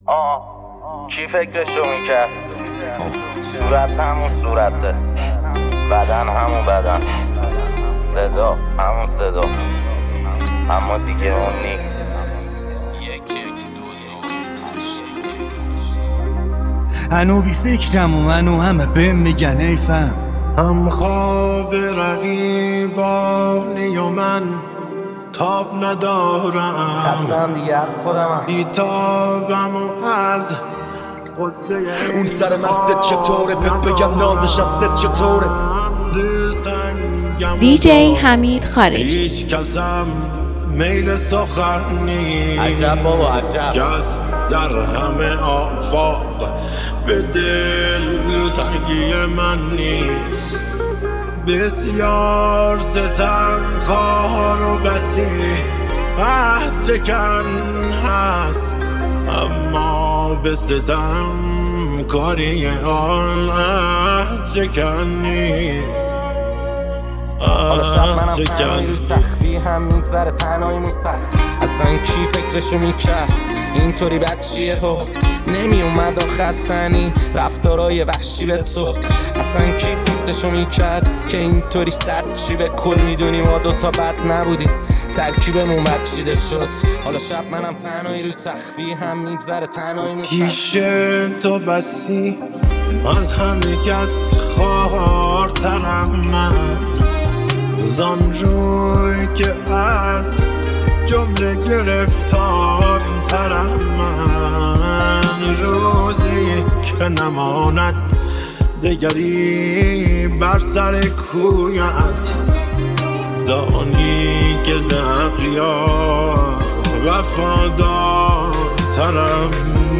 تلفیقی از سبک‌های رپ، پاپ، و هیپ‌هاپ